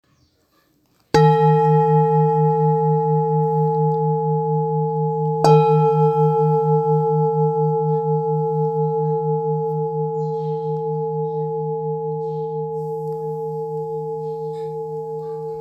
Jambati Singing Bowl Singing Bowl, Buddhist Hand Beaten, Double Dorje Carved
Material Seven Bronze Metal
Jamabati bowl is a hand-beaten bowl.
It can discharge an exceptionally low dependable tone.